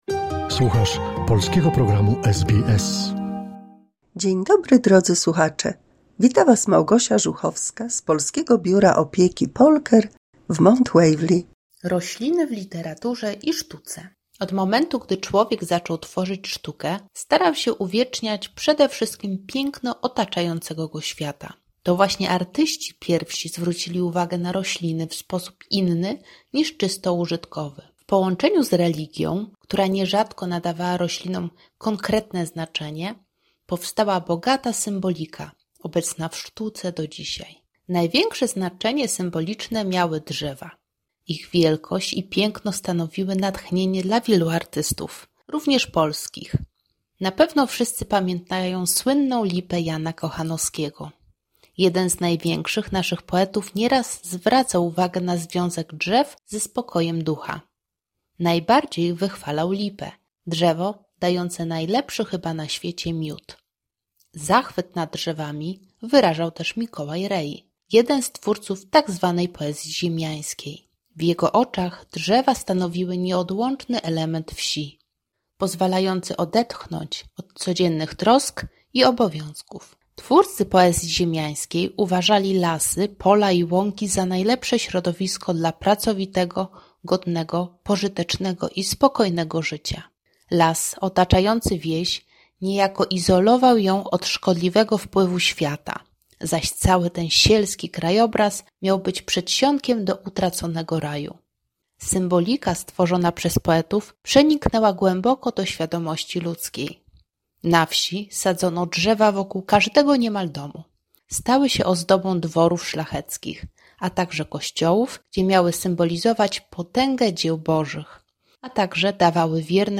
197 słuchowisko dla polskich seniorów